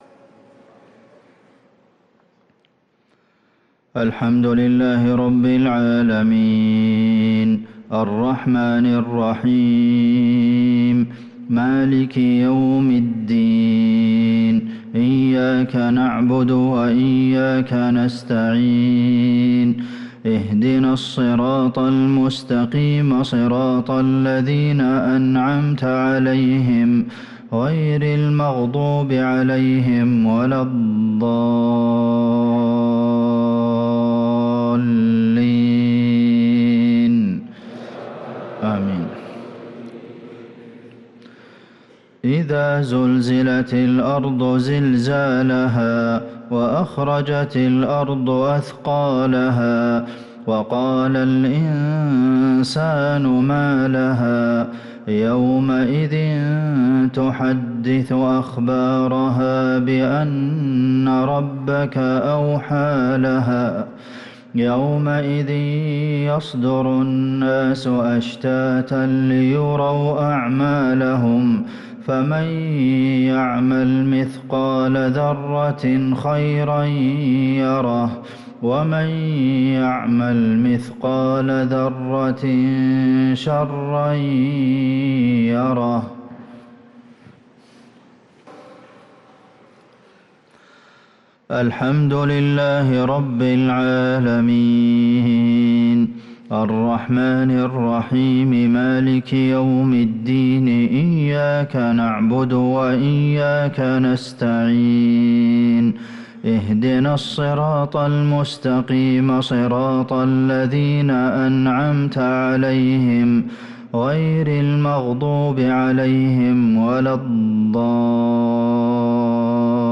صلاة المغرب للقارئ عبدالمحسن القاسم 20 محرم 1445 هـ
تِلَاوَات الْحَرَمَيْن .